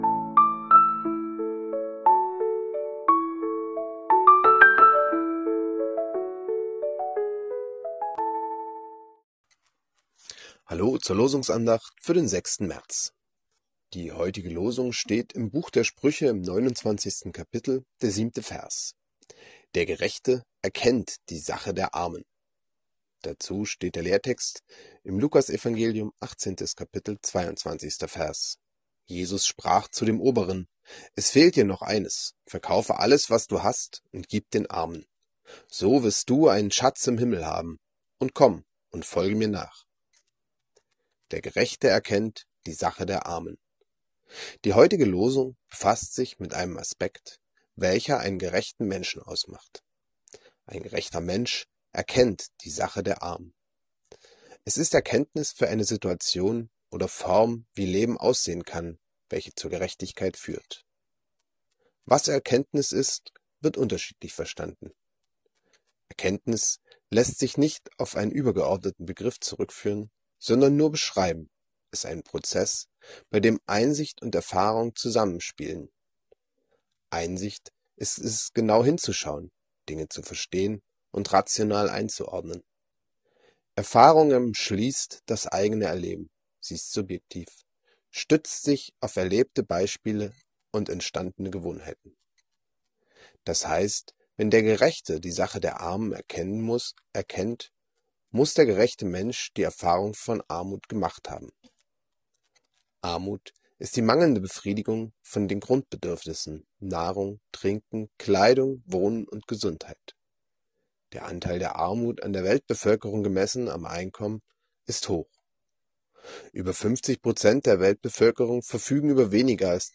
Losungsandacht für Freitag, 06.03.2026 – Prot.